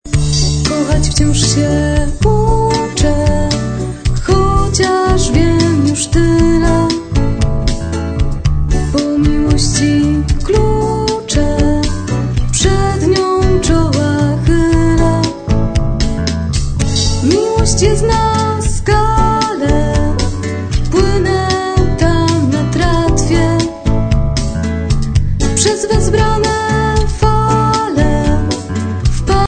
Mocno elektryczna i energetyczna.